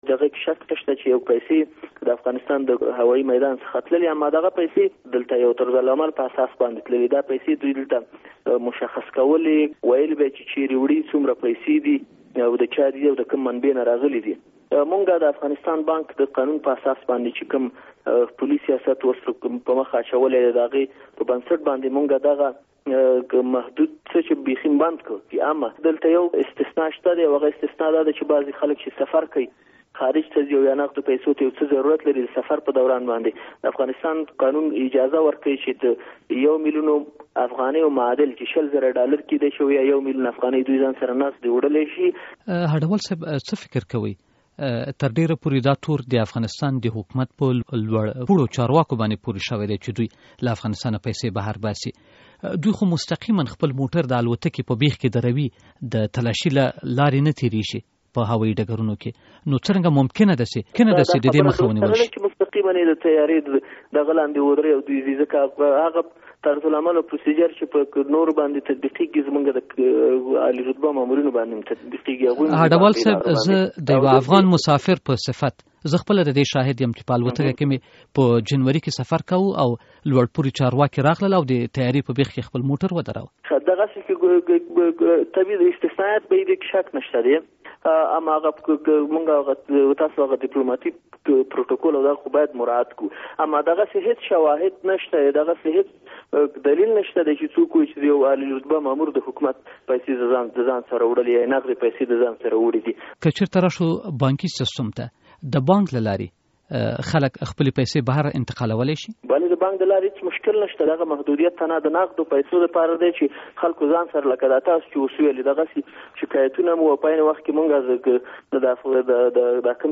له فضل هډه وال سره مرکه